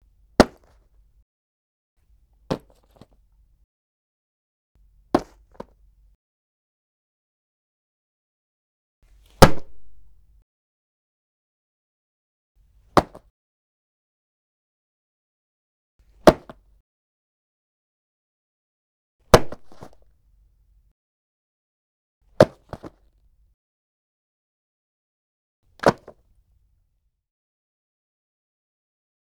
household
Dropping Bag Onto Floor